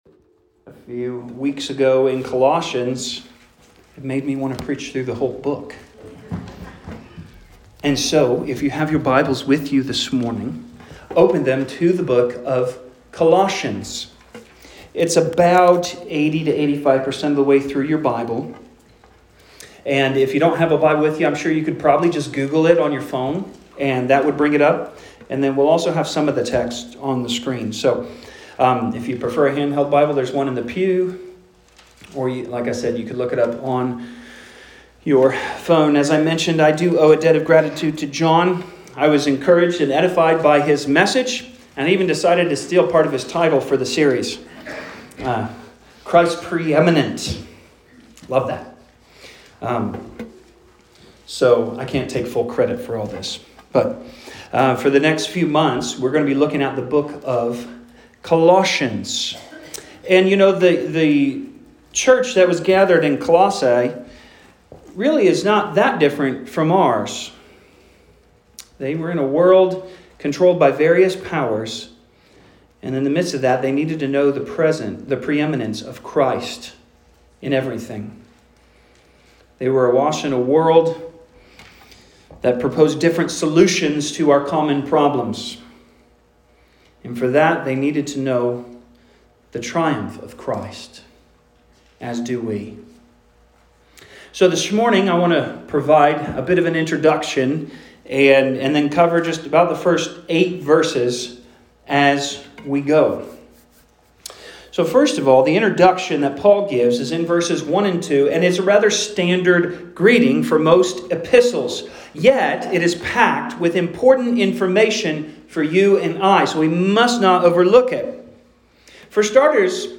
Sermons | Grace Gospel Church